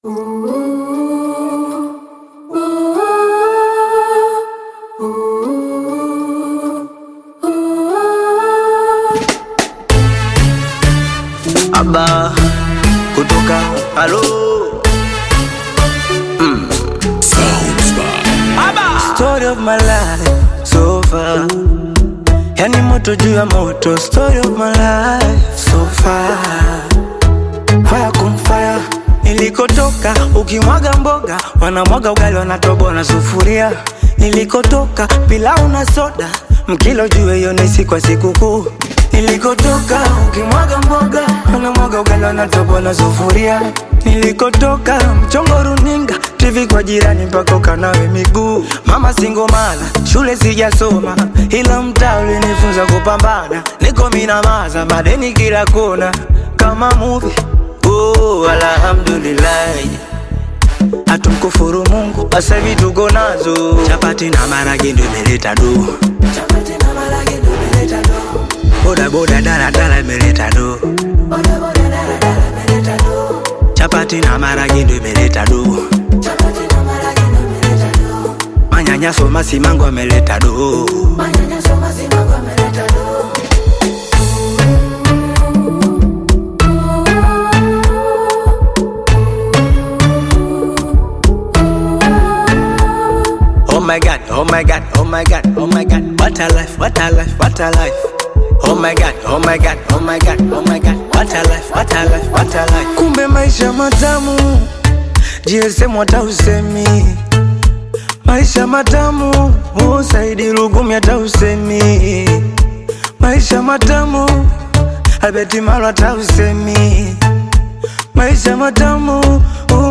Bongo Flava Thanksgiving music